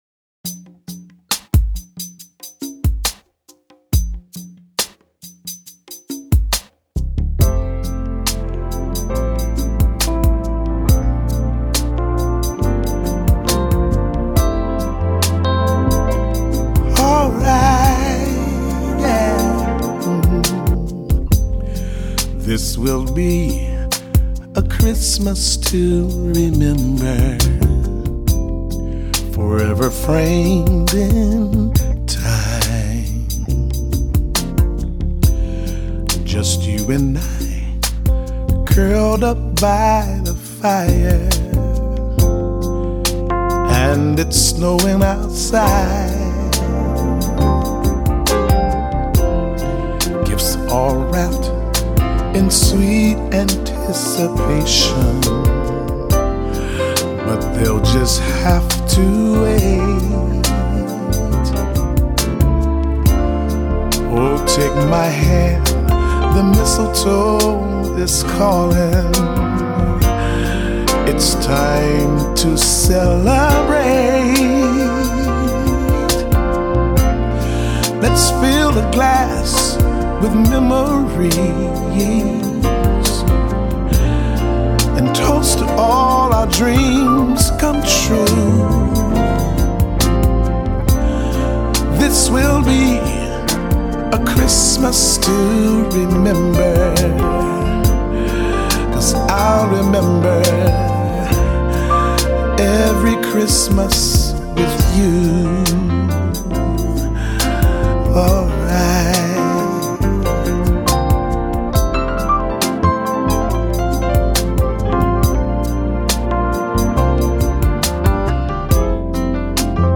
ballad Keys, drums, male vx